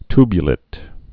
(tbyə-lĭt, -lāt, ty-) also tu·bu·lat·ed (-lātĭd)